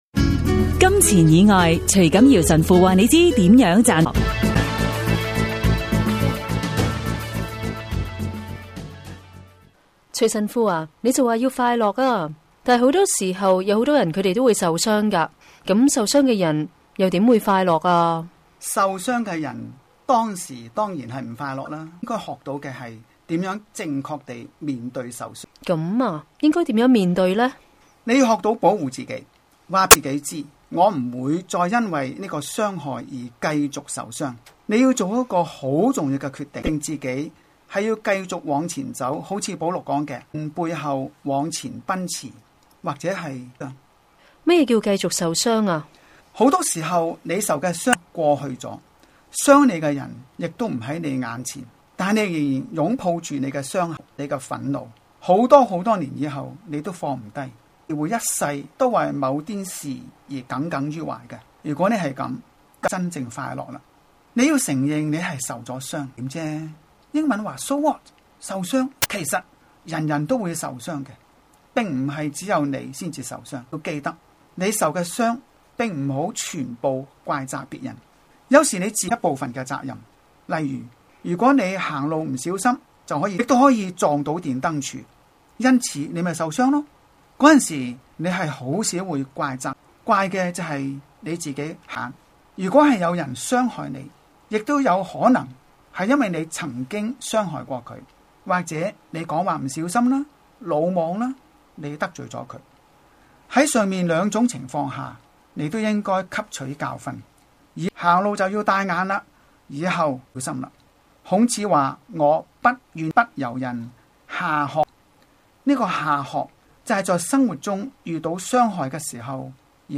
自2007年底，我們在新城財經台推出「金錢以外」電台廣播節目，邀請不同講者及團體每晚以五分鐘和我們分享金錢以外能令心靈快樂、生命富足的生活智慧，讓聽眾感悟天主的美善和睿智。